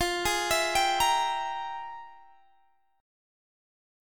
FmM11 Chord
Listen to FmM11 strummed